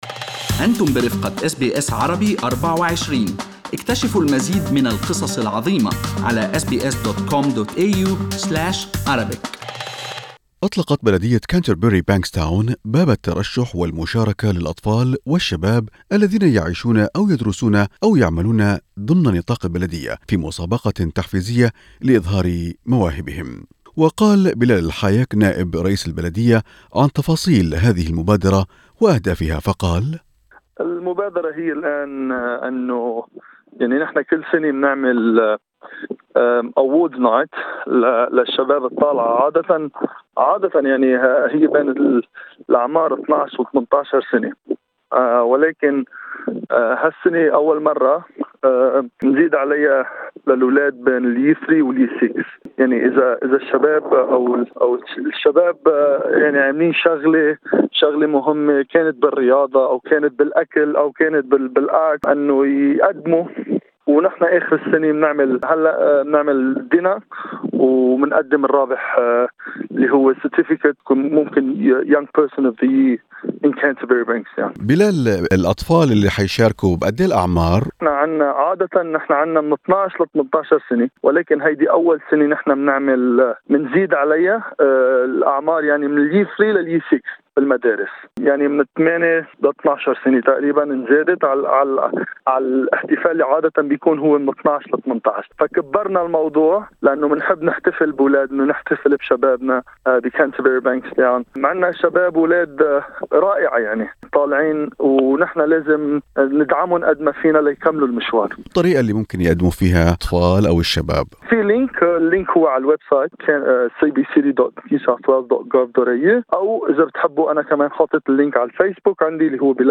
لقاء خاص مع بلال الحايك نائب رئيس بلدية كانتربري - بانكستاون يتحدث فيه عن المبادرة الجديدة لتحفيز الأطفال والشباب في المنطقة لإطلاق مخيلتهم الإبداعية ومساهمات يرونها هامة وملهمة لمجتمهم المحلي.